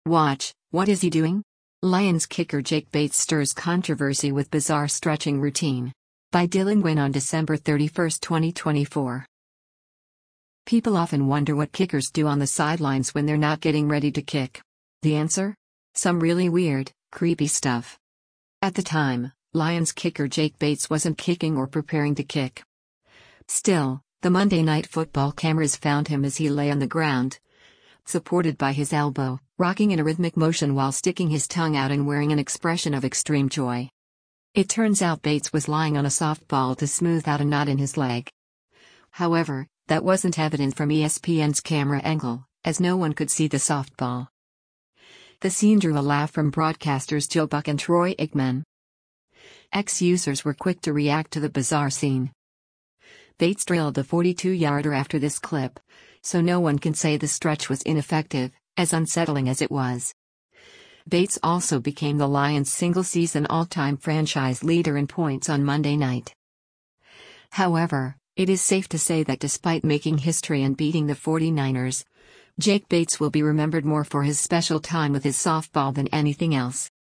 The scene drew a laugh from broadcasters Joe Buck and Troy Aikman.